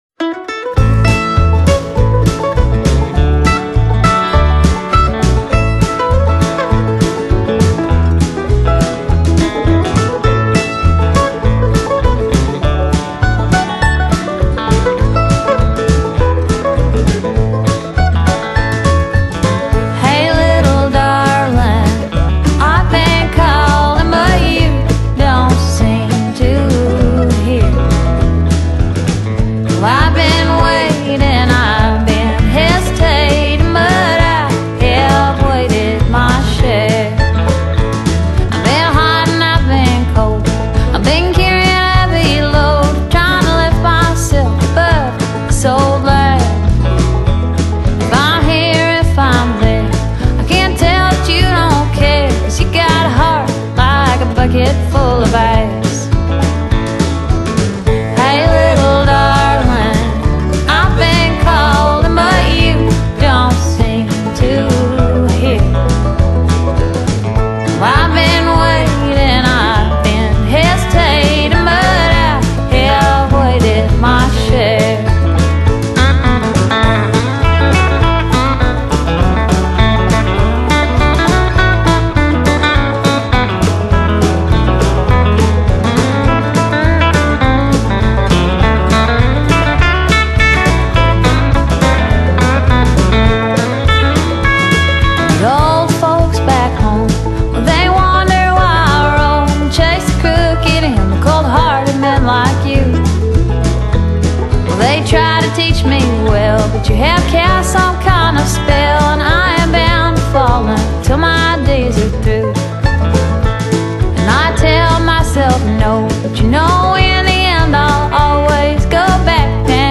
鄉村民謠
乾脆利落，慢的民謠聲線慵懶純粹，音樂簡單，原始，純淨，又不失美麗。